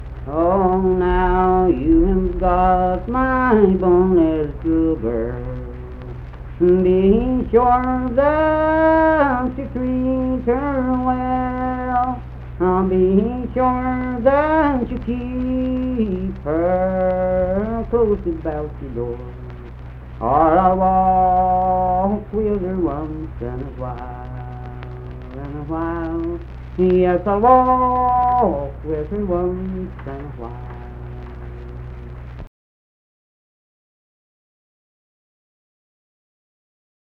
Unaccompanied vocal music
Verse-refrain 1(5).
Voice (sung)
Kirk (W. Va.), Mingo County (W. Va.)